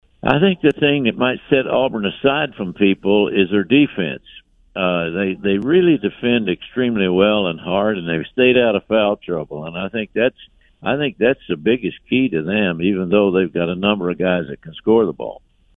Sonny Smith, longtime Auburn basketball coach and now the color analyst for Auburn Basketball, said what sets the Tigers apart from other teams is their defense.